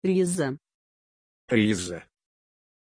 Pronunciation of Rezzo
pronunciation-rezzo-ru.mp3